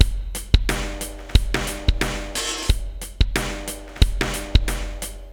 Rock-07.wav